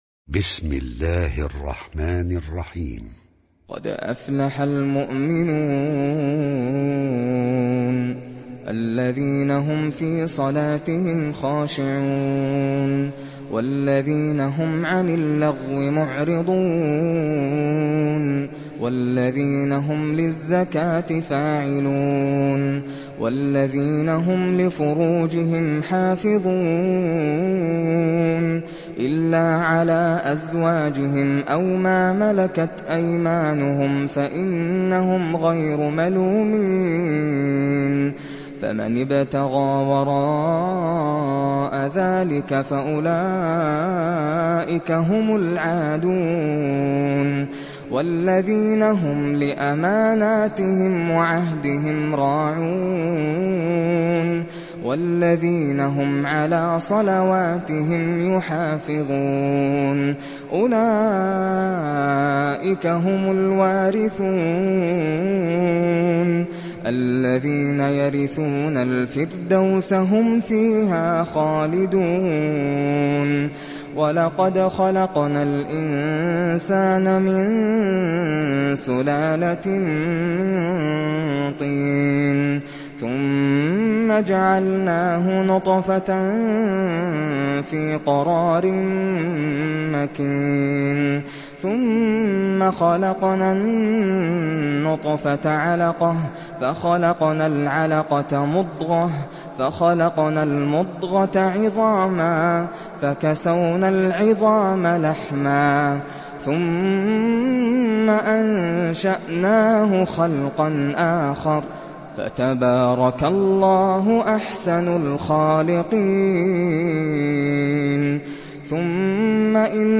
সূরা আল-মু’মিনূন ডাউনলোড mp3 Nasser Al Qatami উপন্যাস Hafs থেকে Asim, ডাউনলোড করুন এবং কুরআন শুনুন mp3 সম্পূর্ণ সরাসরি লিঙ্ক